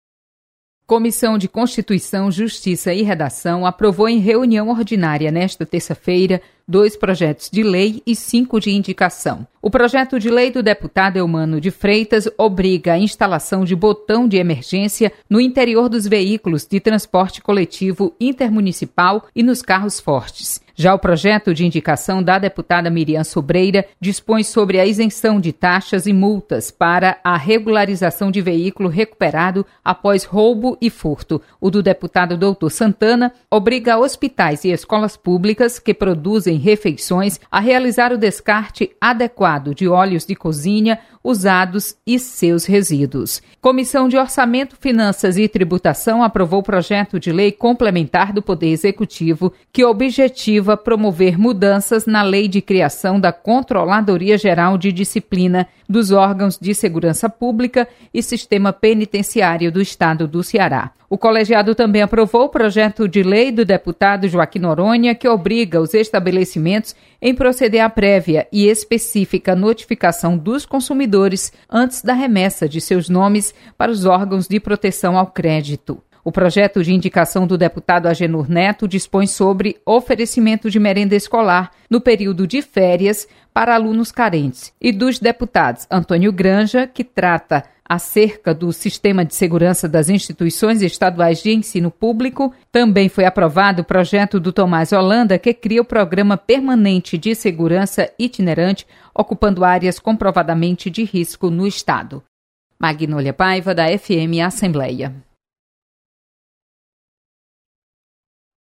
Acompanhe resumo das comissões técnicas permanentes da Assembleia Legislativa com a repórter